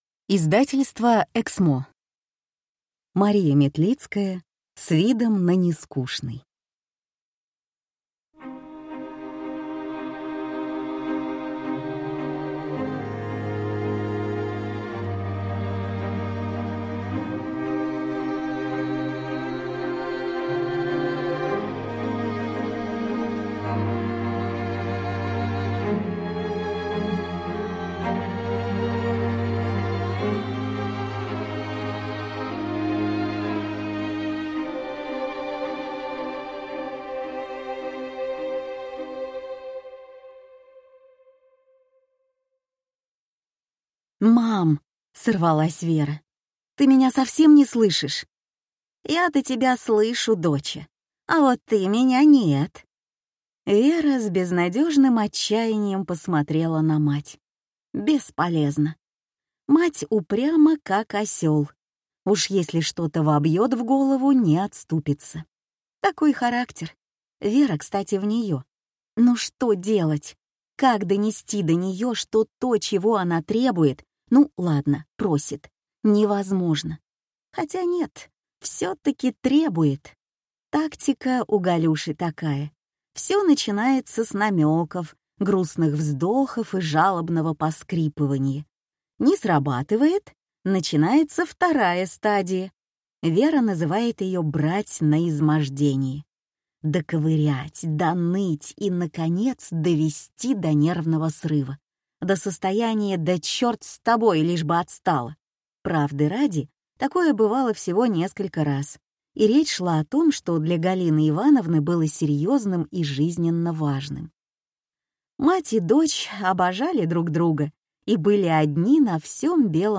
Аудиокнига С видом на Нескучный | Библиотека аудиокниг